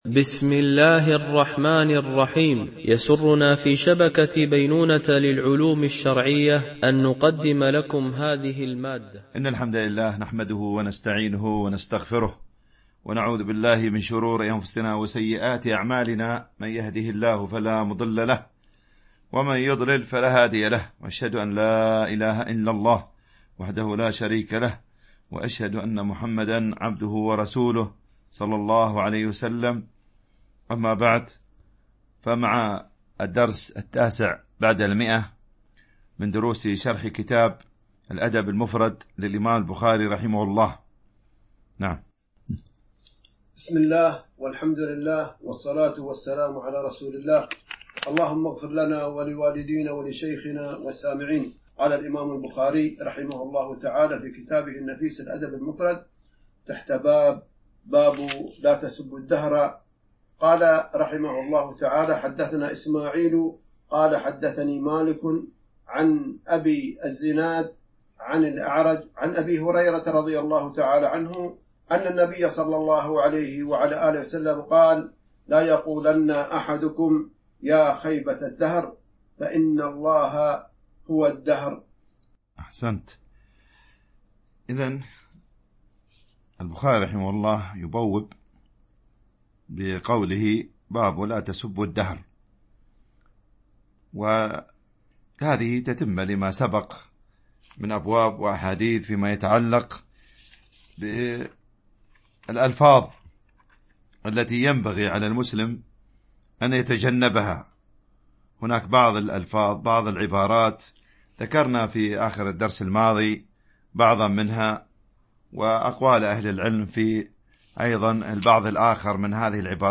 شرح الأدب المفرد للبخاري ـ الدرس 109 ( الحديث 769 - 775 )